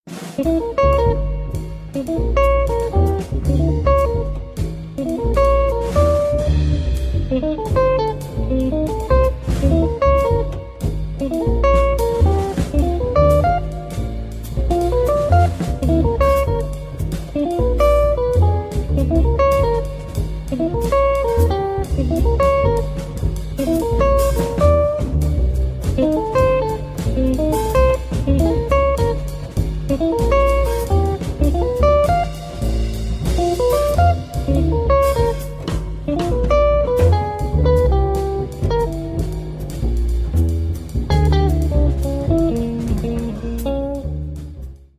Contemporary Jazz Guitar meets Pitch Class Set Improvisation
Guitar
Bass
Drums